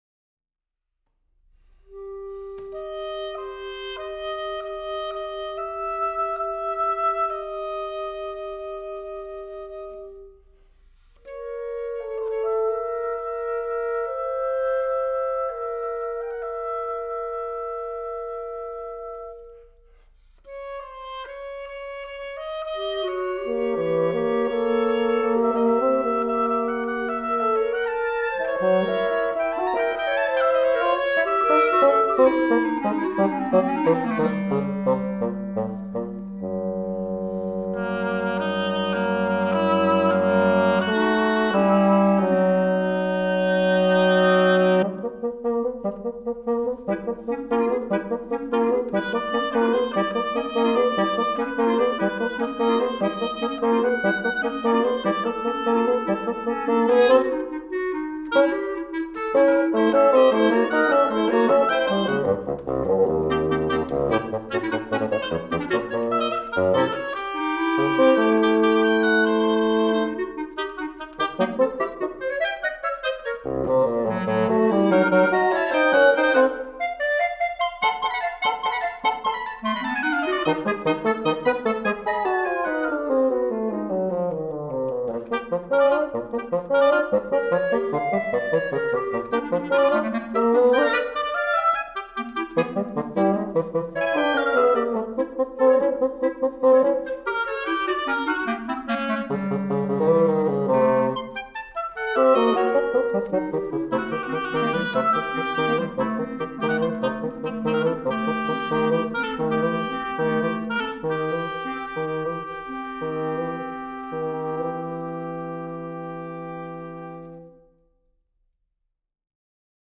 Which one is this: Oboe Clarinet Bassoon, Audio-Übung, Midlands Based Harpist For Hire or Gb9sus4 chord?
Oboe Clarinet Bassoon